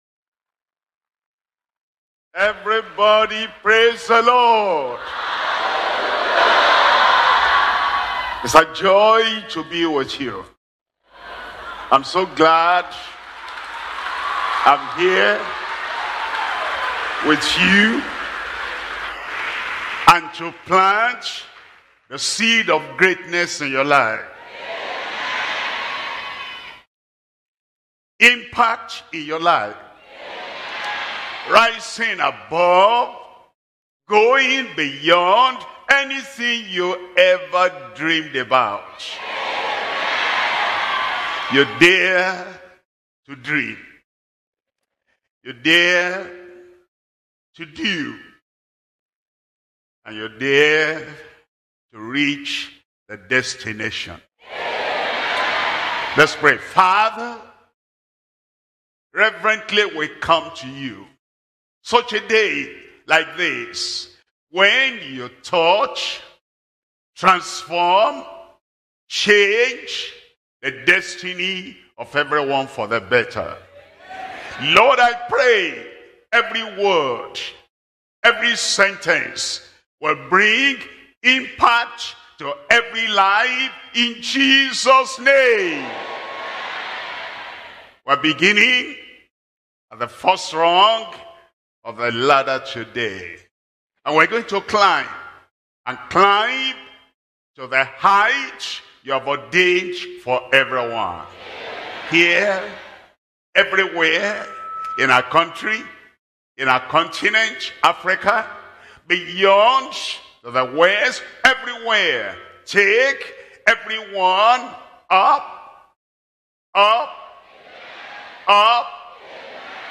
SERMONS – Deeper Christian Life Ministry Australia